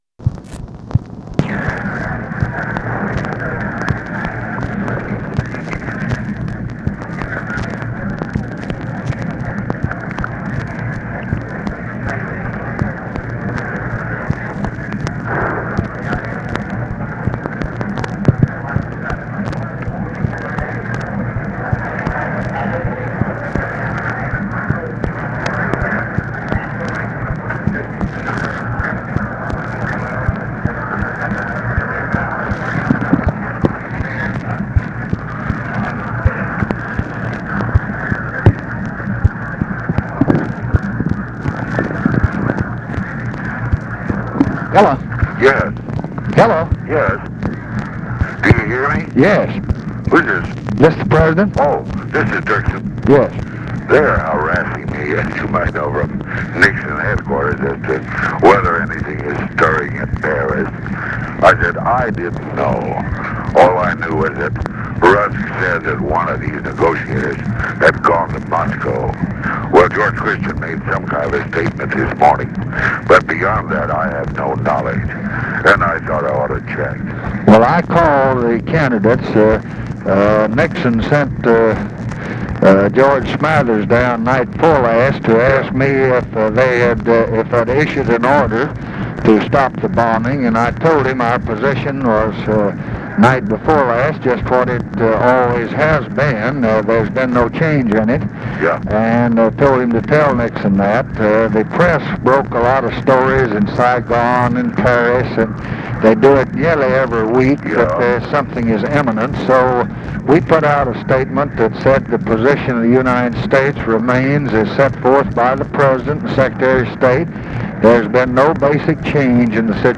Conversation with EVERETT DIRKSEN and OFFICE NOISE, October 16, 1968
Secret White House Tapes